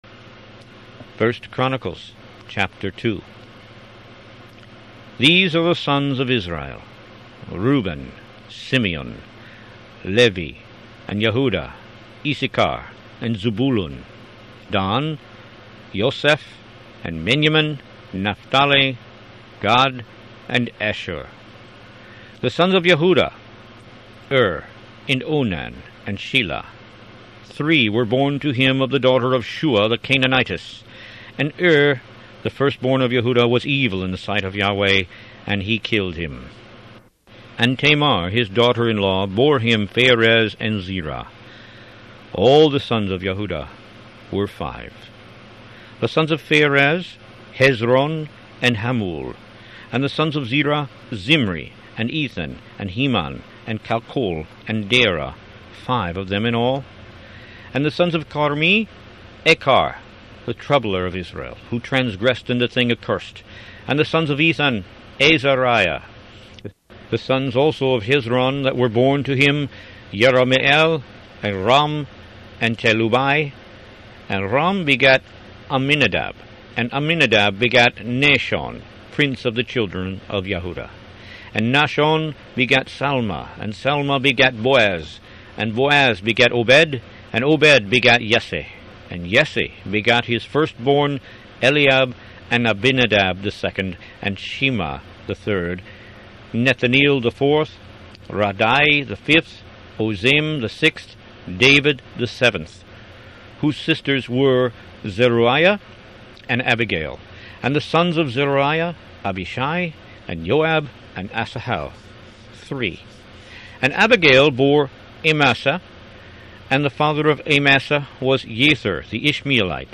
Root > BOOKS > Biblical (Books) > Audio Bibles > Tanakh - Jewish Bible - Audiobook > 13 1Chronicles